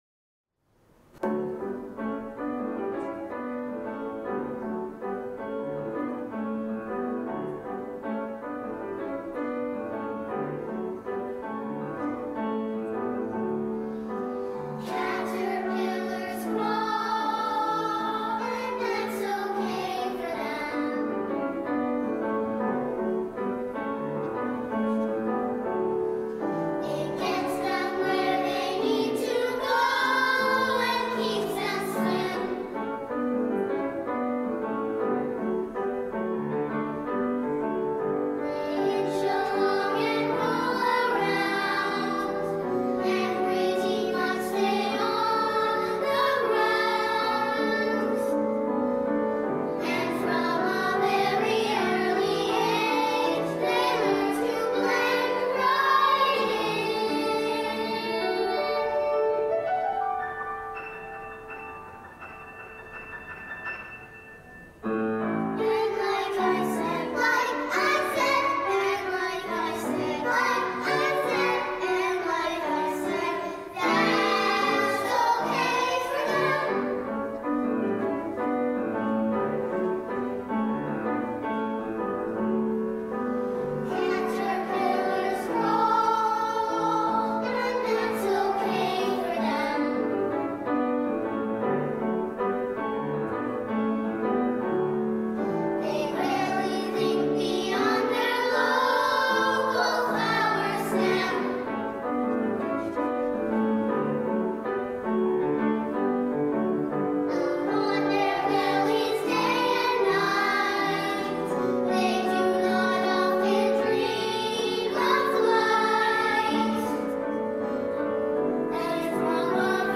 SA, piano